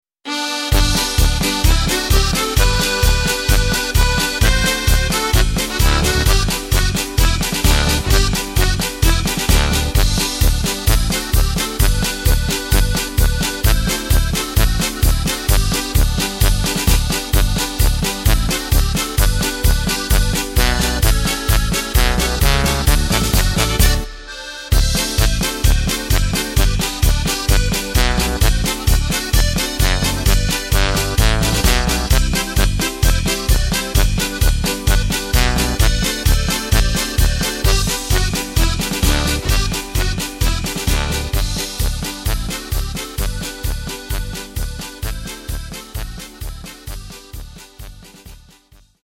Takt:          2/4
Tempo:         130.00
Tonart:            G
Playback mp3 Demo